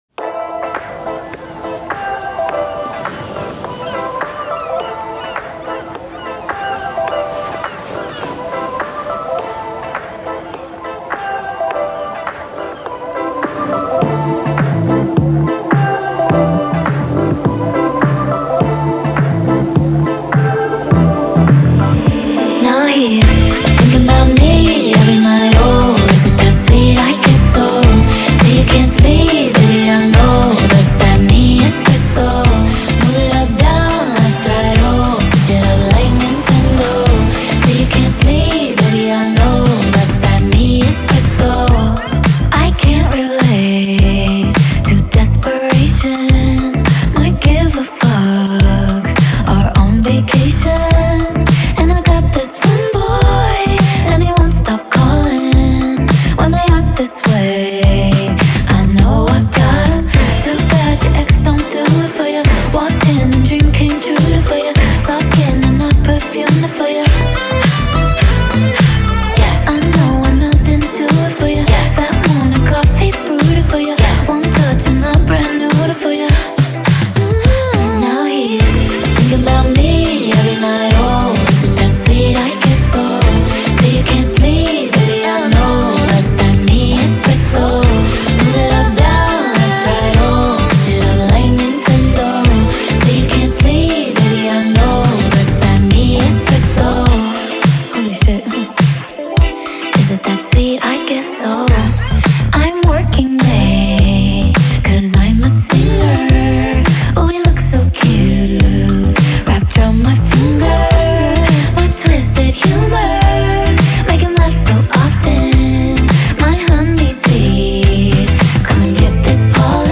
With its high energy tempo and catchy sounds